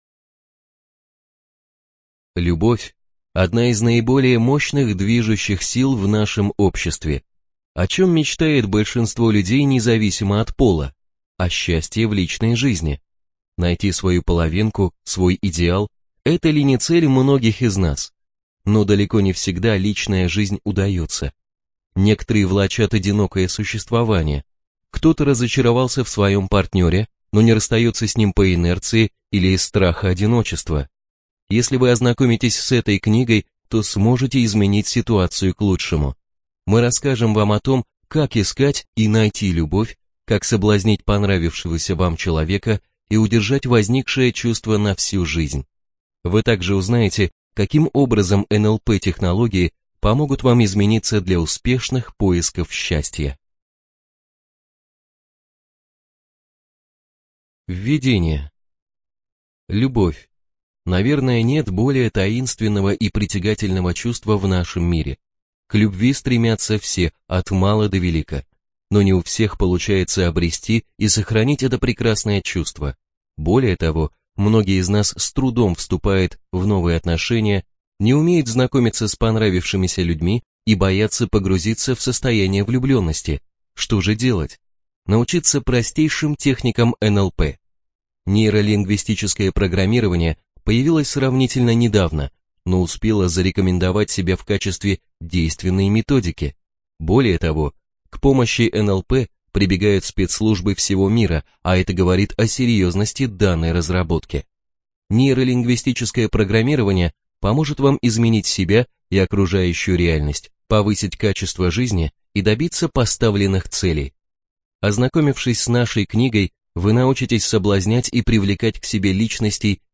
Все секреты соблазнения от лучших мастеров НЛП собраны в одной аудиокниге!